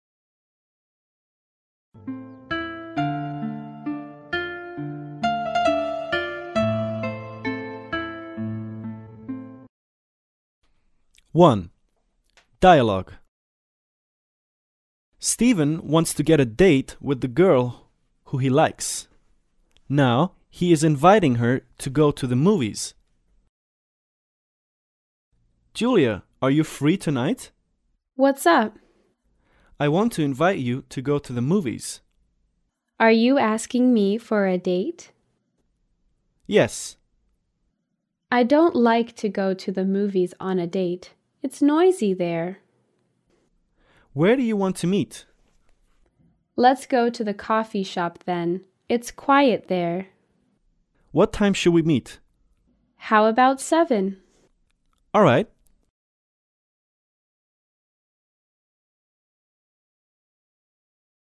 对话